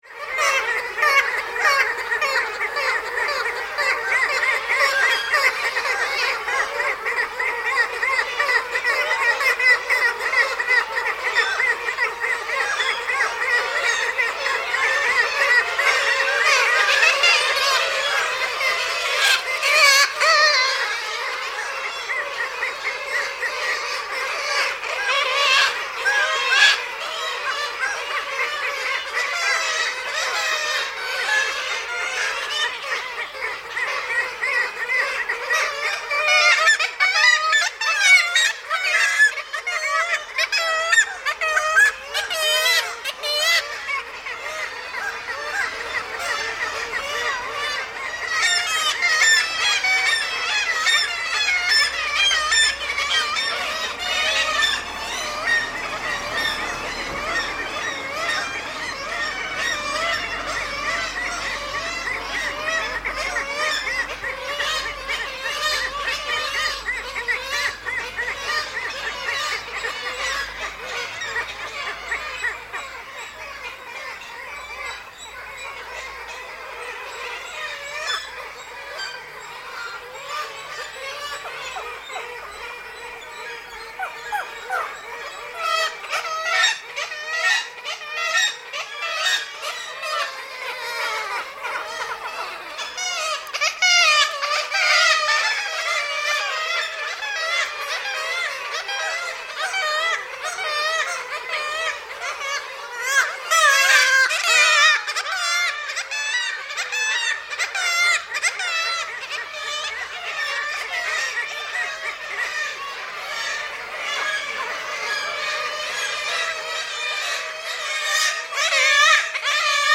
Å village is full of seagulls - they never stop talking and singing!